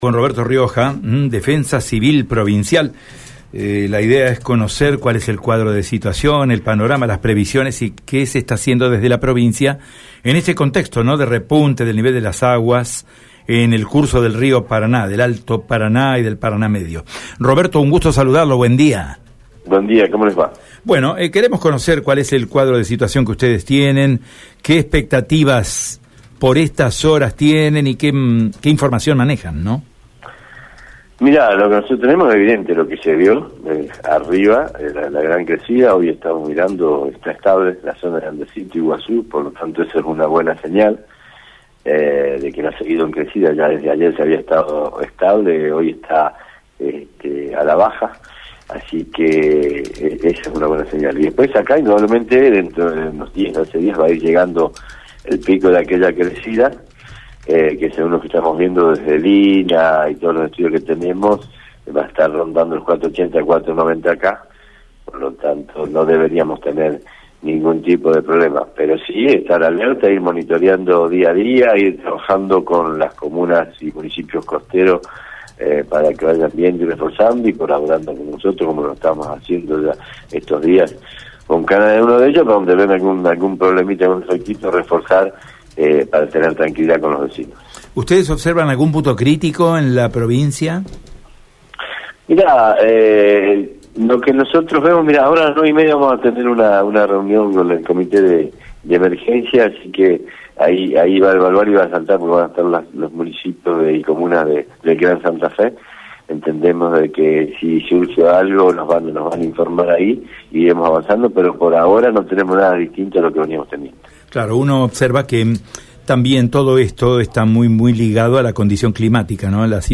Así lo consideró en Radio EME, el secretario de Protección Civil de la provincia de Santa Fe, Roberto Rioja.
Escucha la palabra de Roberto Rioja en Radio EME: